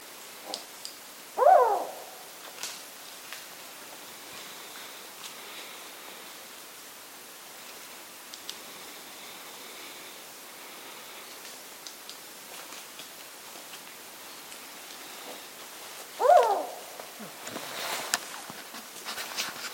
Lechuza Negra (Strix huhula)
Nombre en inglés: Black-banded Owl
Localidad o área protegida: Parque Provincial Cruce Caballero
Condición: Silvestre
Certeza: Vocalización Grabada